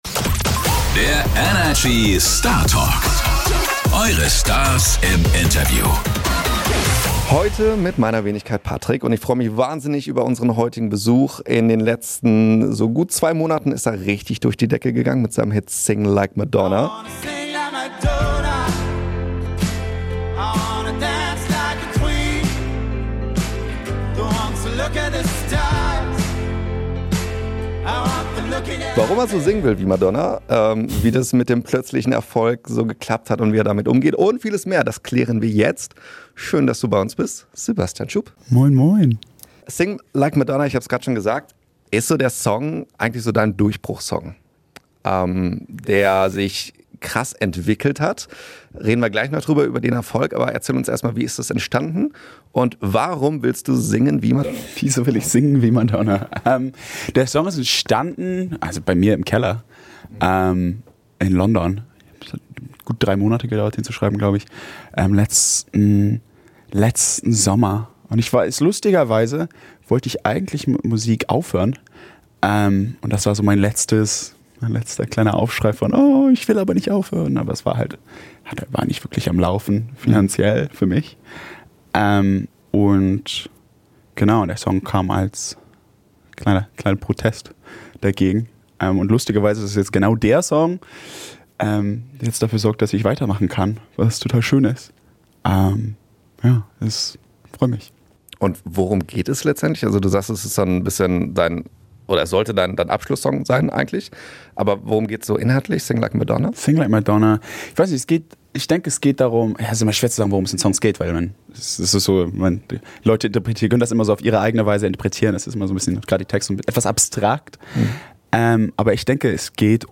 Der gebürtige Hamburger hat unerwartet einen leicht zu hörenden englischen Dialekt, weil er als Teenager mit seinen Eltern nach London zog.